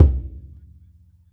SingleHit_QAS10782.WAV